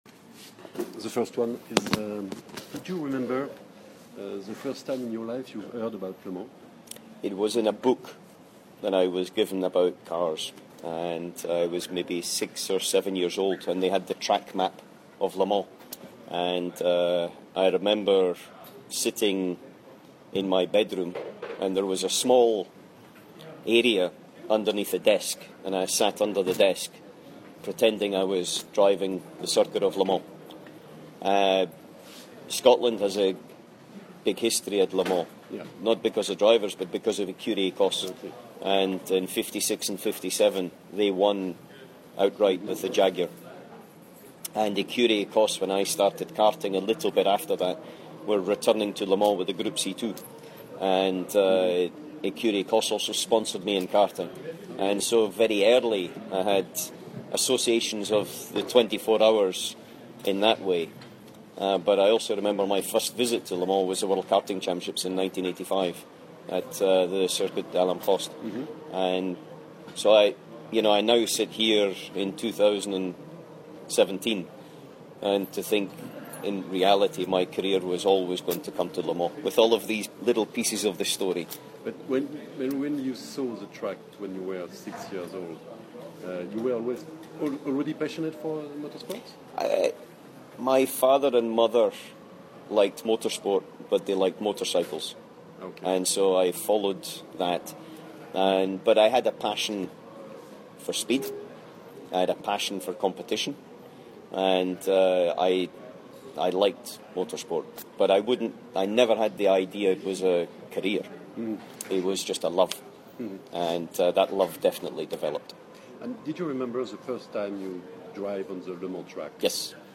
Opus meets three-time winner of the 24 Hours Le Mans, Allan McNish, for an in depth interview for The Official 24 Hours LeMans Opus. Allan McNish Interview for The Official 24 Hours Le Mans Opus Interviewer: The first one is do you remember the first time in your life you heard about Le Mans?